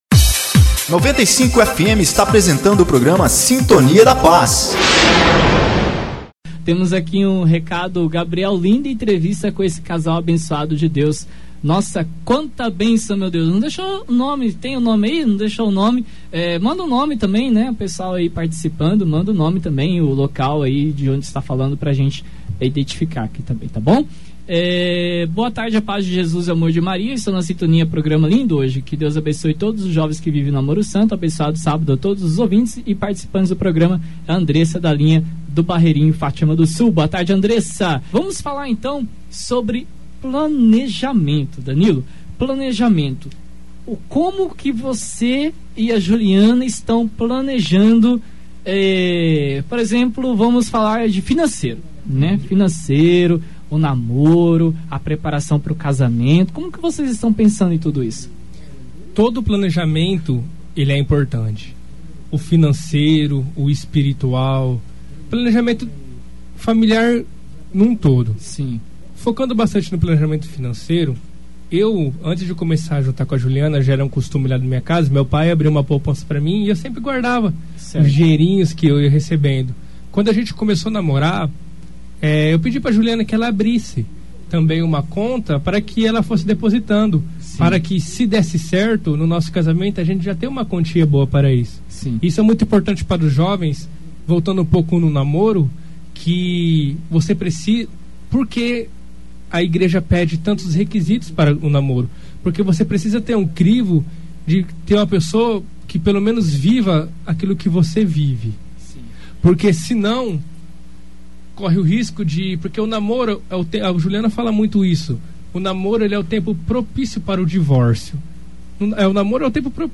Bate Papo